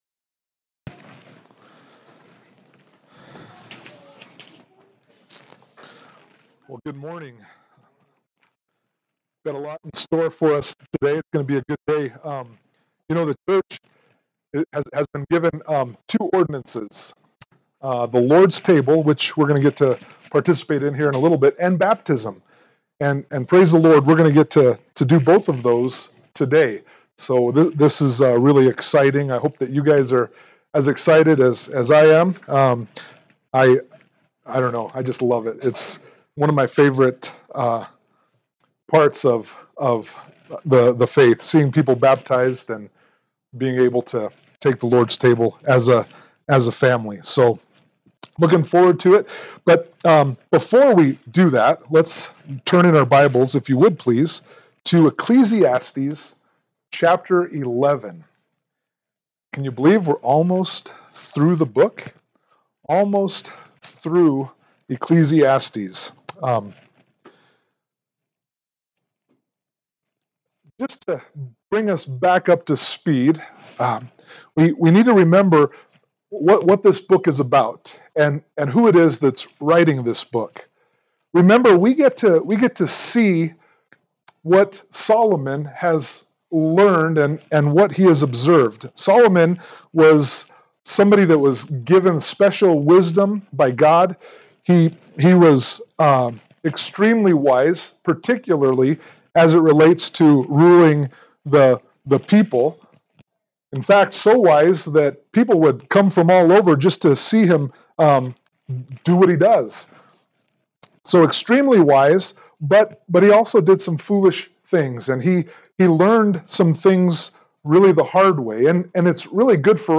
Ecclesiastes 11 Service Type: Sunday Morning Worship « Ecclesiastes Chapter 10